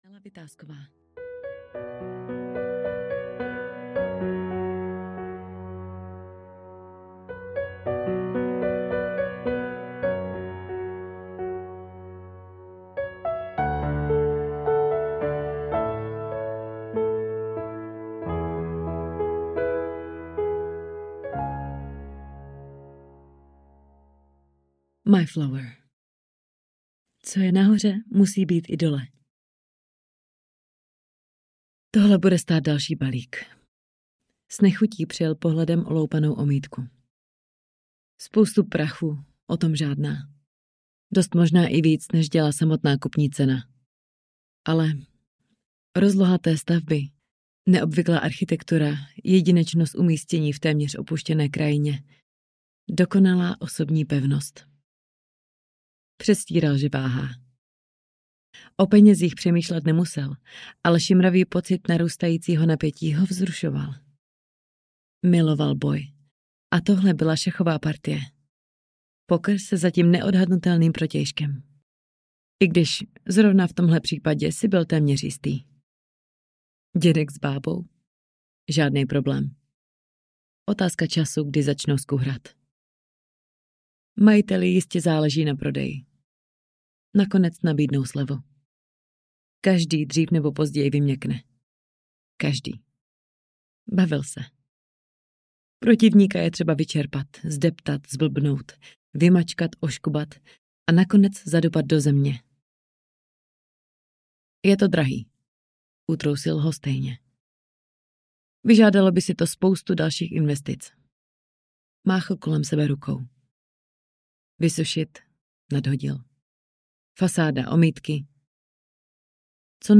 Paranoia audiokniha
Ukázka z knihy